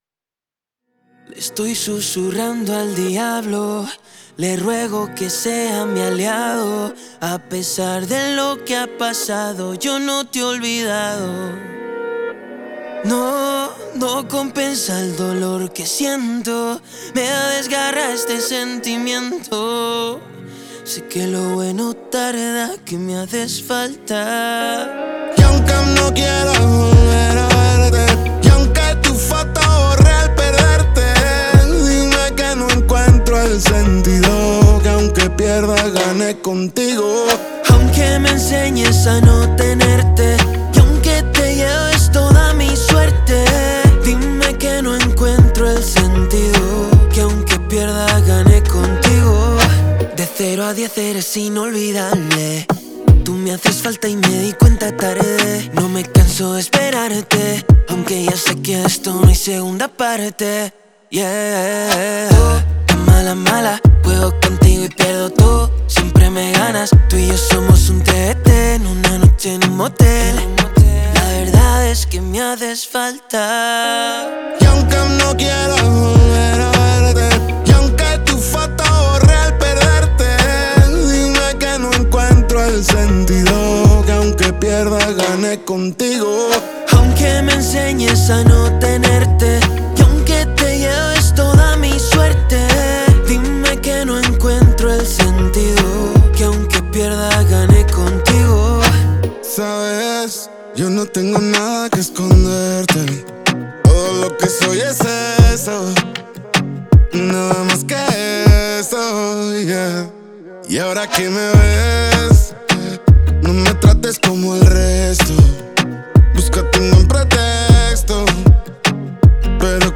это зажигательная песня в жанре латин-поп